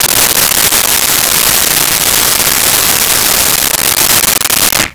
Blender On Whip
Blender on Whip.wav